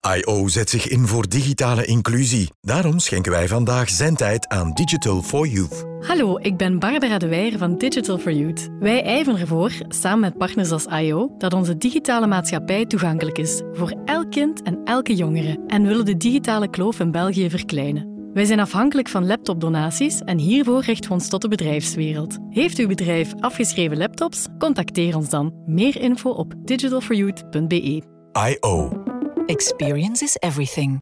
To put the collaboration in the spotlight, iO developed an audio ad that will soon be heard in the popular podcast De 7. This ad calls on podcast listeners to do their bit for a digital future for everyone.
Sound Studio: Raygun
Digital For Youth (NL) - radio spot.mp3